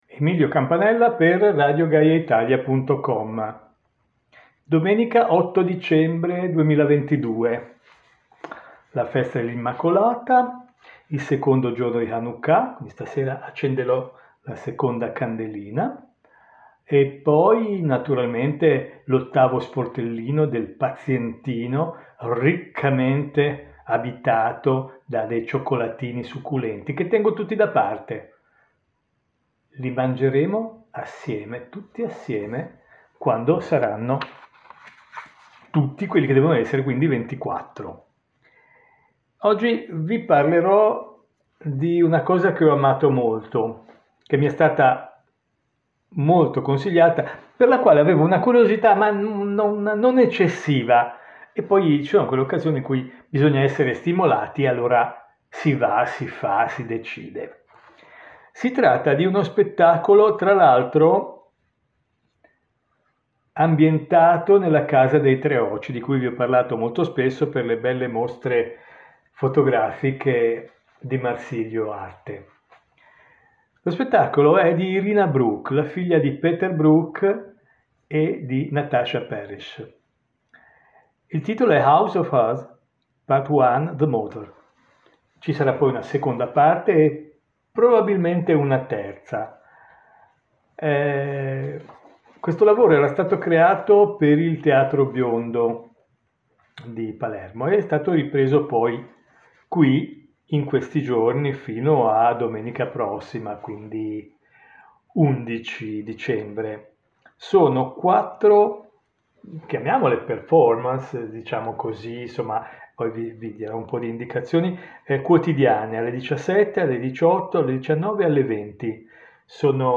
Mai sessanta minuti furono meglio spesi, ci racconta il nostro critico d’Arte in questo podcast esclusivo direttamente dalla Casa dei Tre Oci di Venezia.